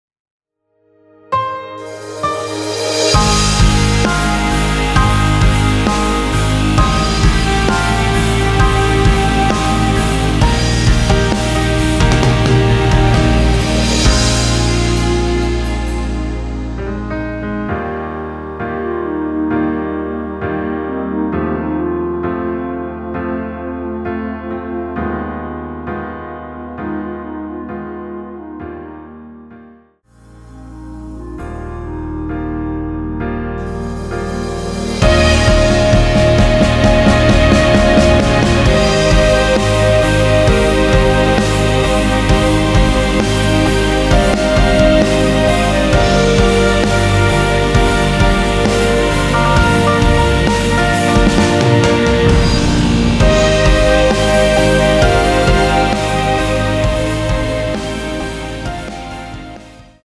원키에서(-1)내린 멜로디 포함된 MR입니다.
Eb
앞부분30초, 뒷부분30초씩 편집해서 올려 드리고 있습니다.